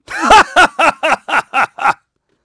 Clause_ice-Vox_Happy6.wav